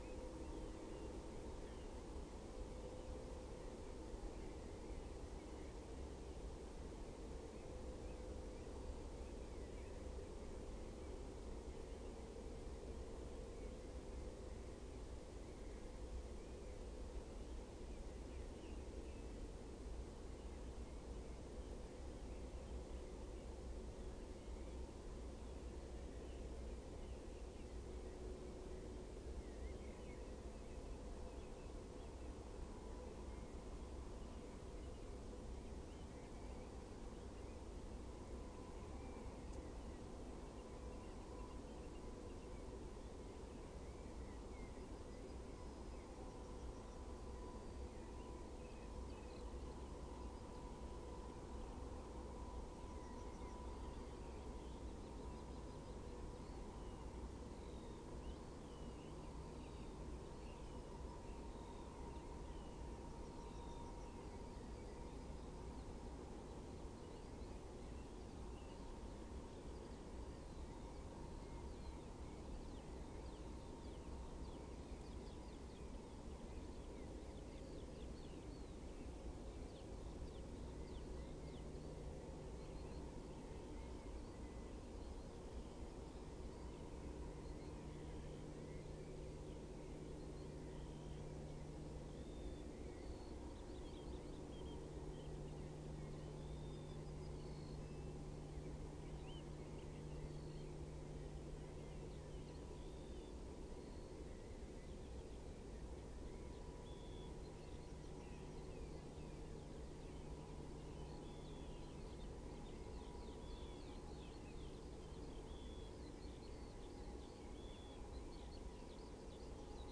Turdus philomelos
Sylvia communis
Emberiza citrinella
Hirundo rustica
Alauda arvensis
Motacilla flava